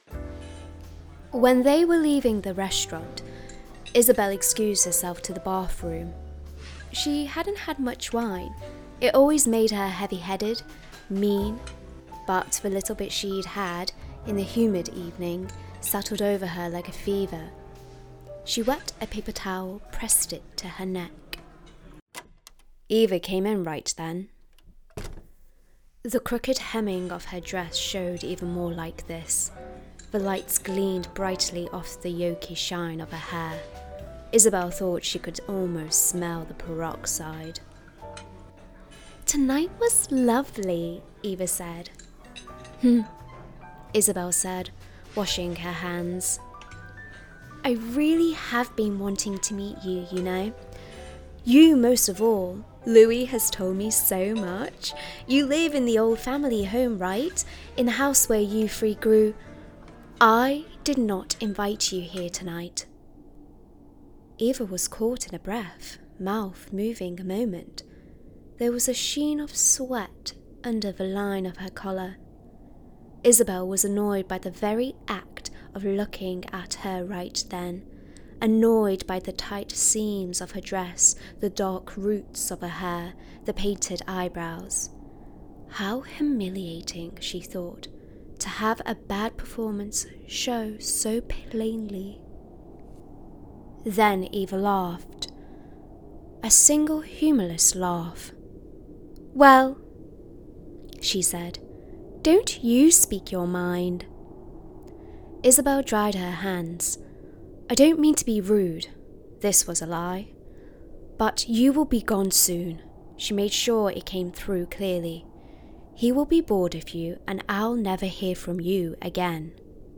Russian, English neutral, Versatile, Youthful, Soft, Calming, Light, Upbeat